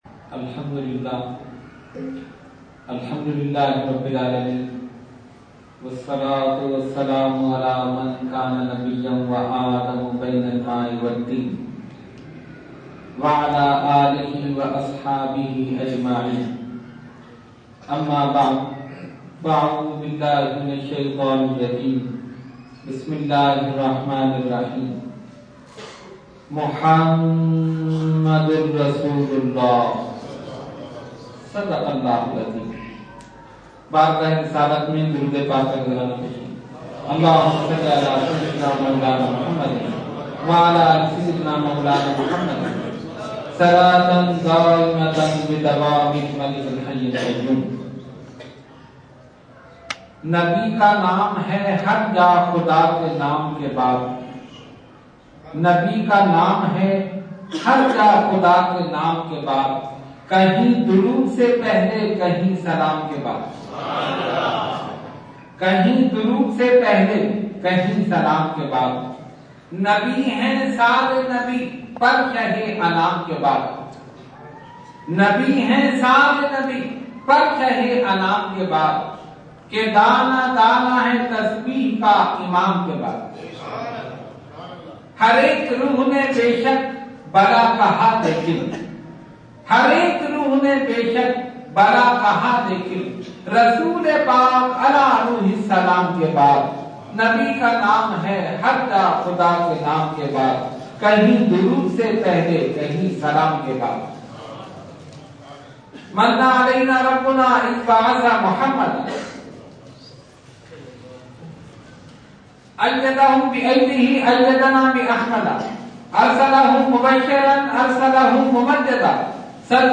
Category : Speech | Language : UrduEvent : Mehfil Milad Akhund Masjid Kharader 10 January 2014